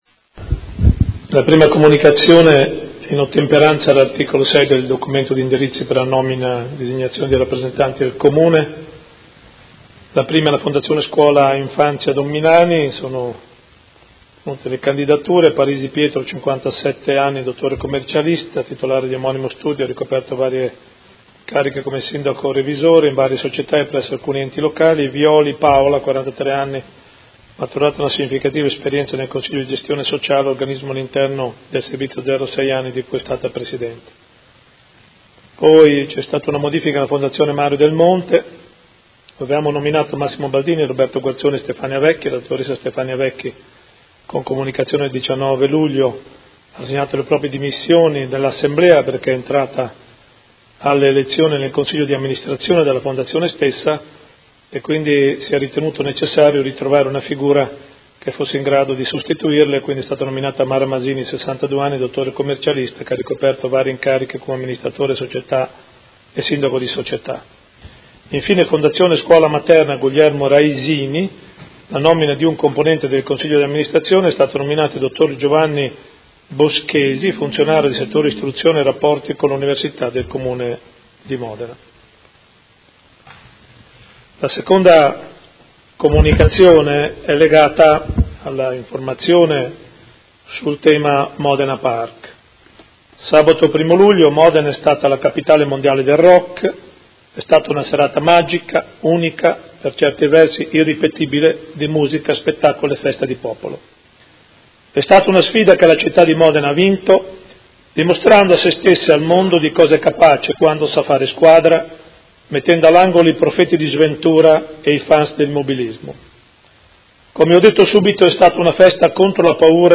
Seduta del 13/07/2017 Comunicazione su nuove nomine, Modena Park e su incontro in Prefettura sulla sicurezza.